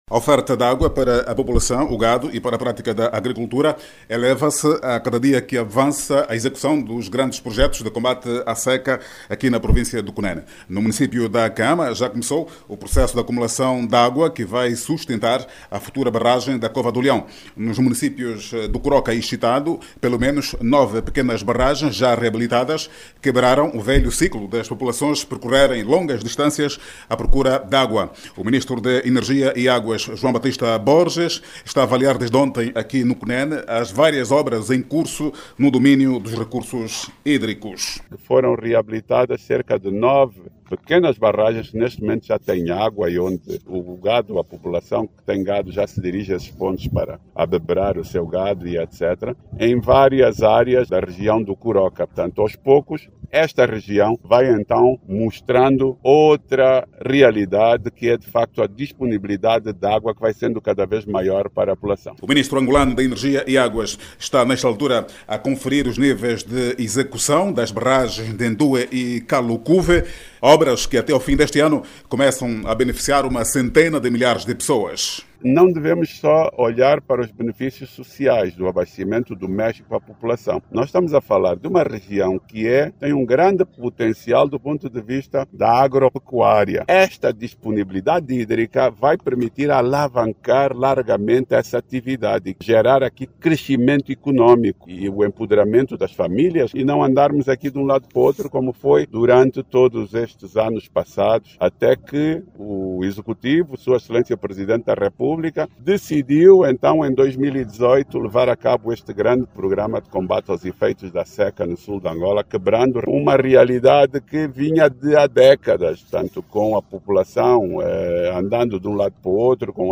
Os projectos estruturantes de combate à seca no município do Coroca, na província do Cunene,  estão a servir a população e o Gado. No Município da Cahama,as barragens reabilitadas começaram igualmente a beneficiar a população que está agora a investir na agricultura. O Ministro da Energia e Aguas, João Batista Borges, esteve no Cunene a avaliar o grau de execução de outros projectos estruturantes de combate à seca na Província. Clique no áudio abaixo e ouça a reportagem